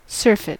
Ääntäminen
US : IPA : [sɜː.fət]